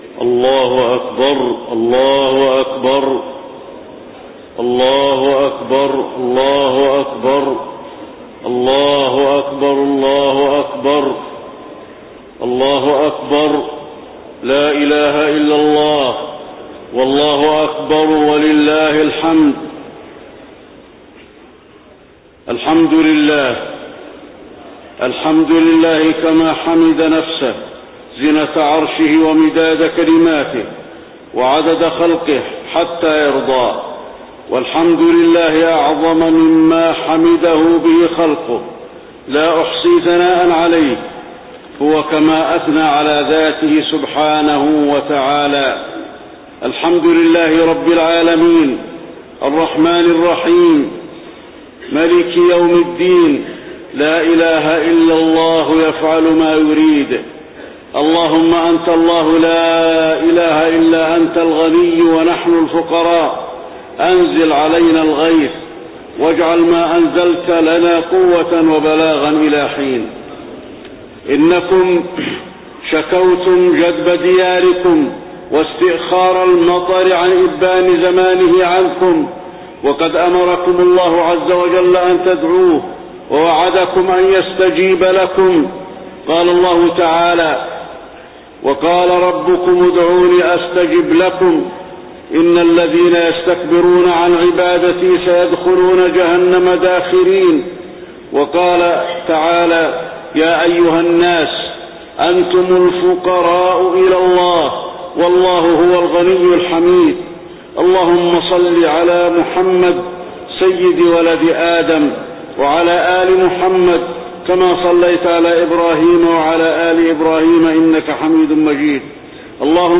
خطبة الاستسقاء - المدينة- الشيخ علي الحذيفي - الموقع الرسمي لرئاسة الشؤون الدينية بالمسجد النبوي والمسجد الحرام
تاريخ النشر ٢ ذو الحجة ١٤٣١ هـ المكان: المسجد النبوي الشيخ: فضيلة الشيخ د. علي بن عبدالرحمن الحذيفي فضيلة الشيخ د. علي بن عبدالرحمن الحذيفي خطبة الاستسقاء - المدينة- الشيخ علي الحذيفي